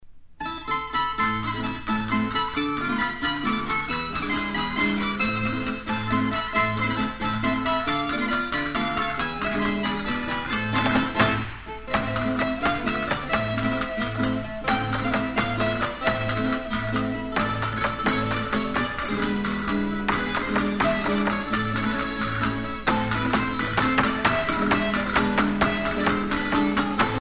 danse : farruca
Pièce musicale éditée